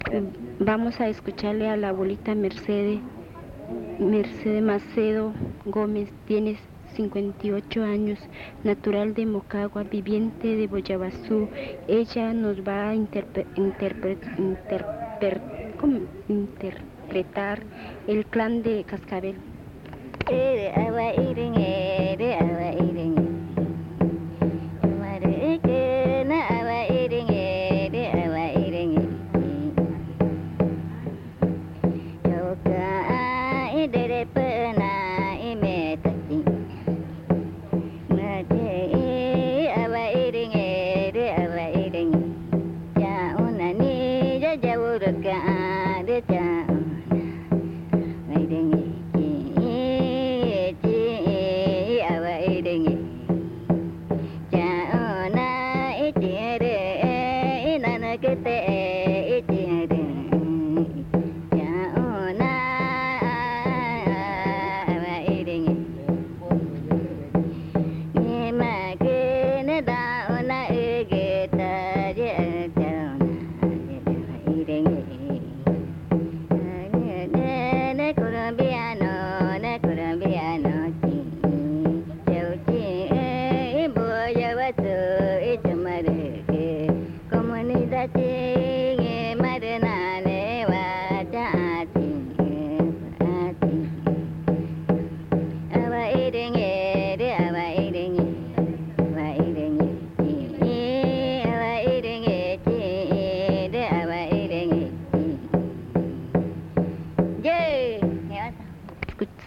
Canto del clan Cascabel
Pozo Redondo, Amazonas (Colombia)
La abuela usa el tambor mientras canta.
The elder uses a drum while singing.